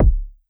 GAR Kick.wav